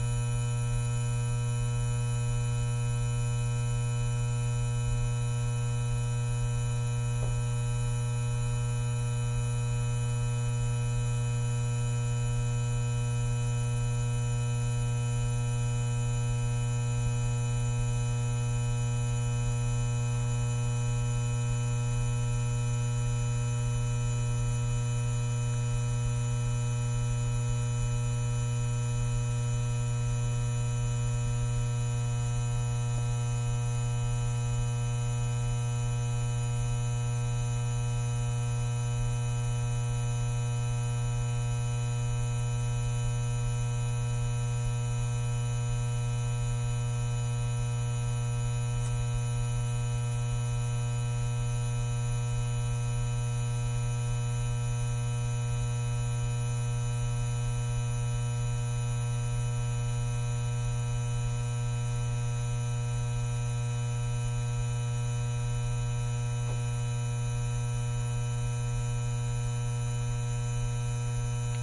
随机" 霓虹灯嗡嗡嗡立体声接近低切的味道6
描述：霓虹灯嗡嗡声嗡嗡声立体声关闭lowcut to taste6.flac
Tag: 关闭 嗡嗡声 低胸 符号 霓虹灯 味道 立体声